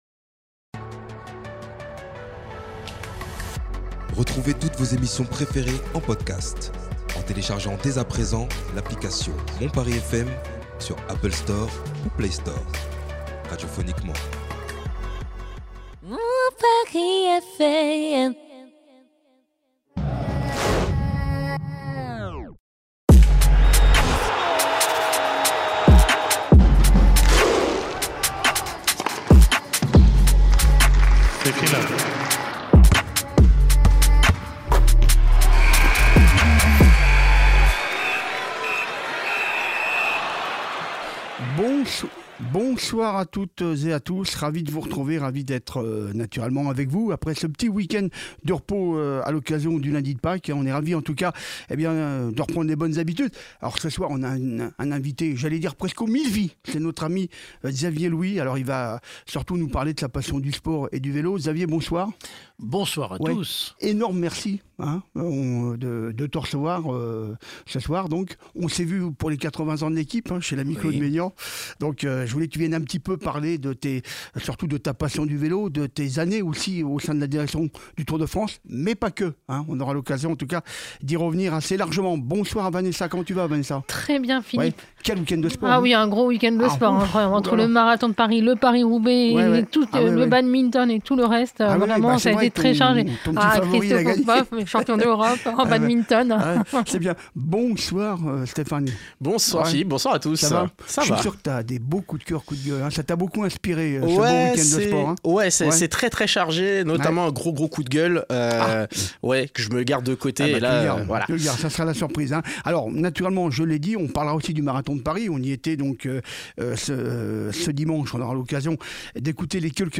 Plus que jamais , l’évènement s’écrira encore ce soir dans nos studios , avec la présence à notre micro d’un invité exceptionnel en la personne de Xavier Louy.